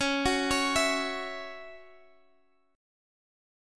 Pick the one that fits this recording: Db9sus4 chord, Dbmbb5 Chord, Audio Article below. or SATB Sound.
Dbmbb5 Chord